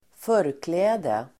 Uttal: [²f'ör:klä:de el.²f'ö:r-]